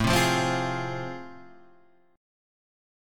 AmM7#5 chord